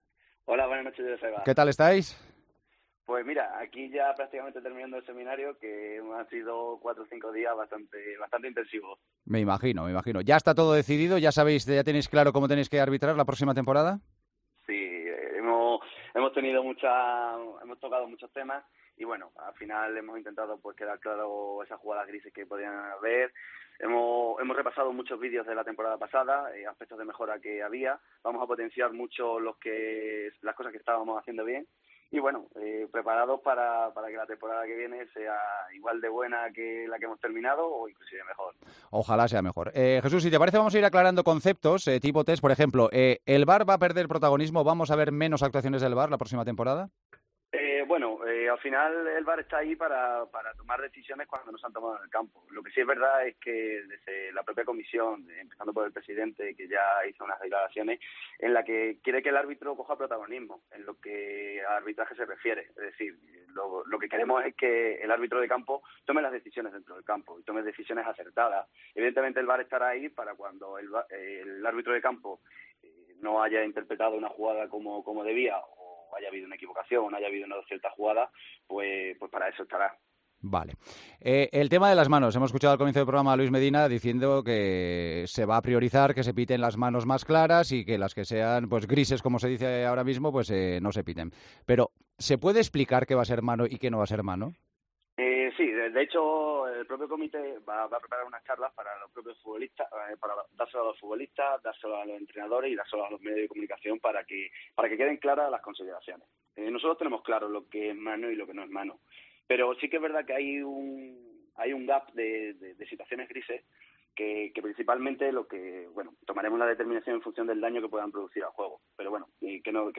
El colegiado extremeño valoró la pasada temporada y confesó los próximos objetivos, en El Partidazo de COPE.